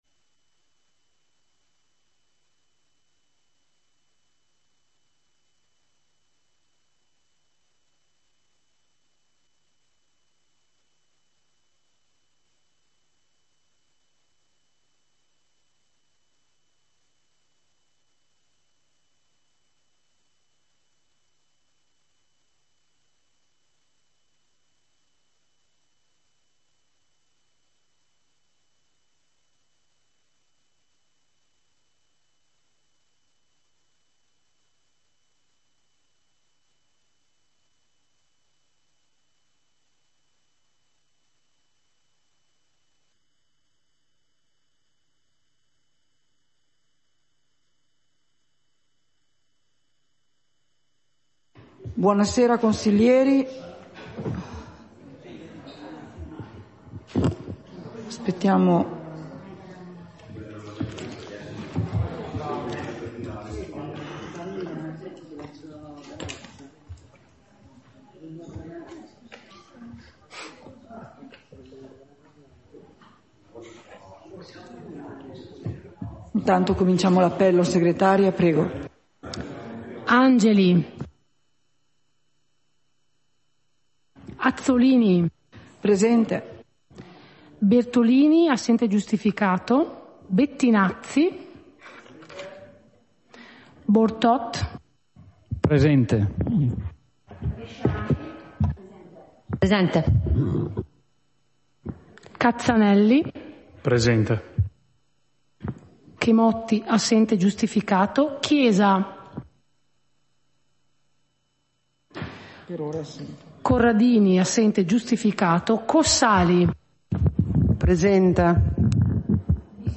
Seduta del consiglio comunale - 25.07.2023